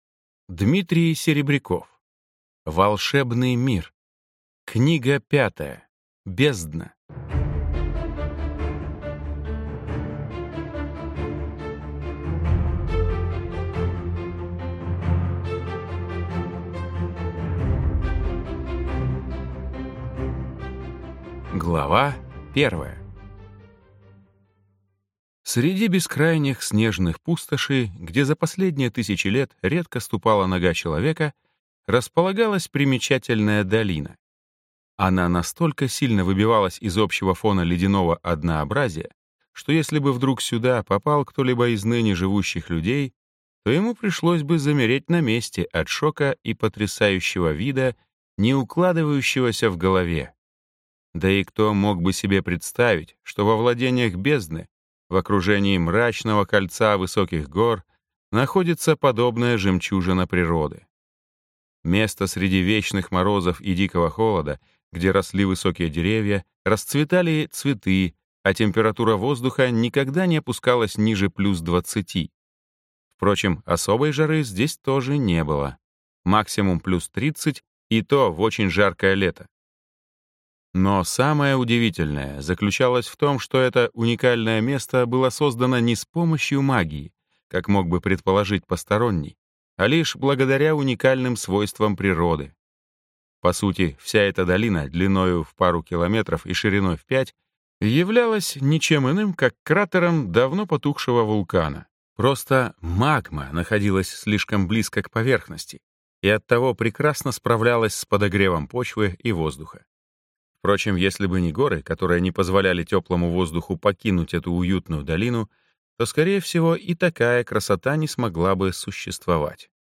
Аудиокнига Бездна | Библиотека аудиокниг